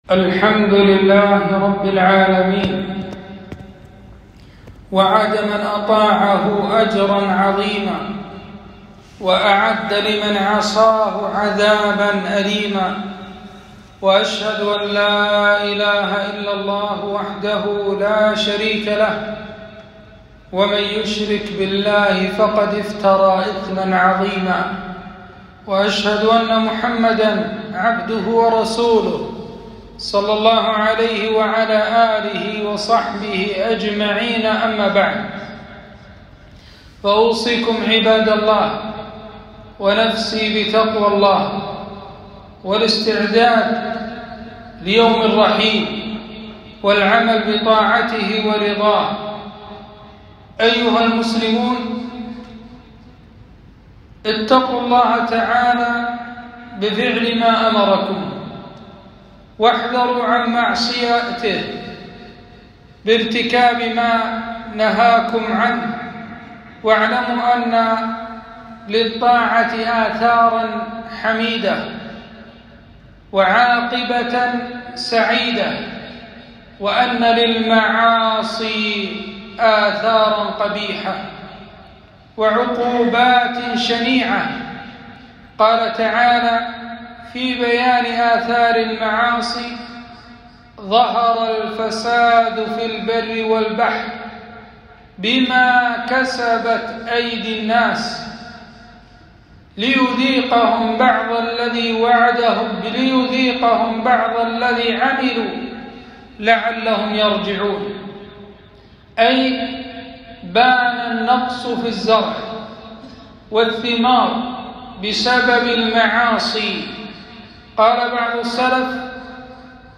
خطبة - آثار المعاصي على الفرد والمجتمع